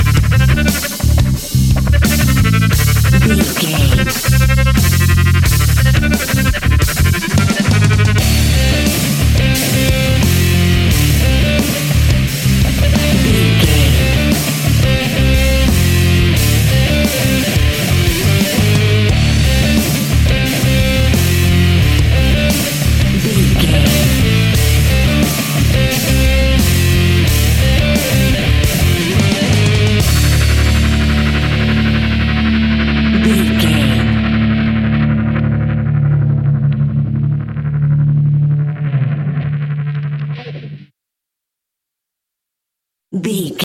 Fast paced
Aeolian/Minor
D
hard rock
heavy metal
distortion
Rock Bass
heavy drums
distorted guitars
hammond organ